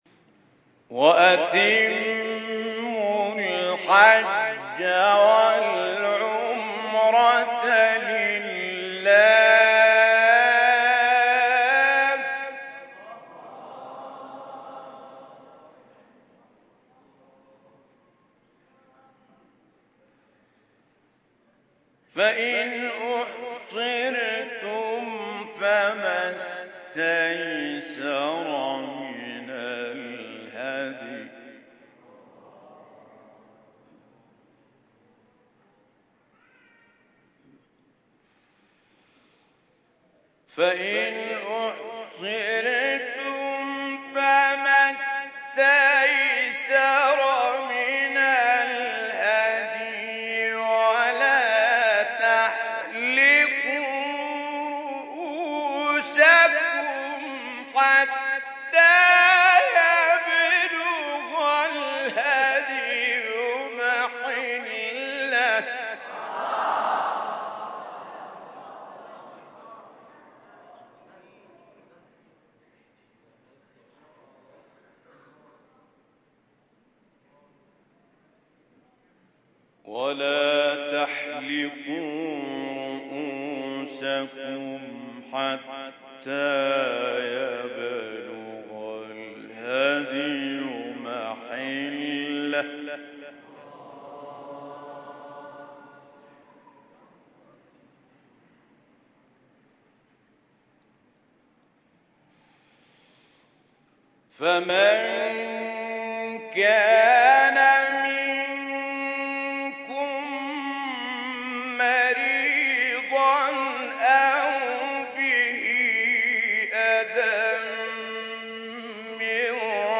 آیه 196 سوره بقره استاد متولی عبدالعال | نغمات قرآن | دانلود تلاوت قرآن